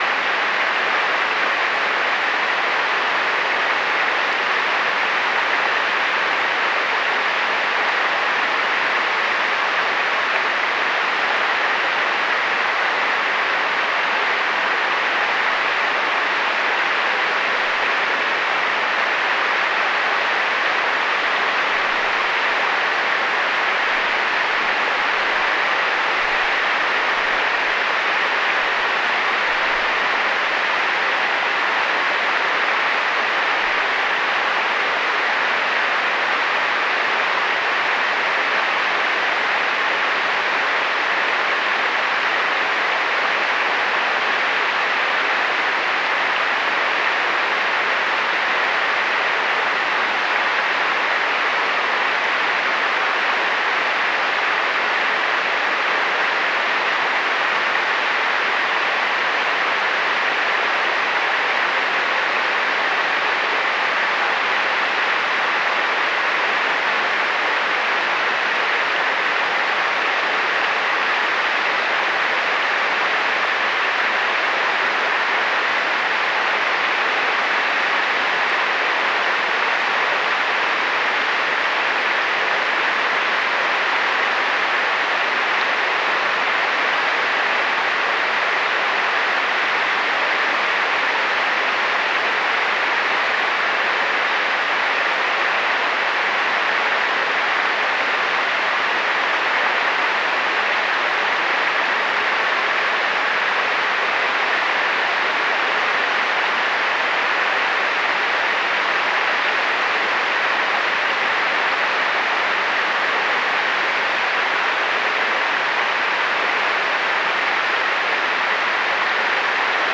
Recorrido sonoro por nuestro país
esa-santa-cruz-el-chalten-salto-del-chorrillo.mp3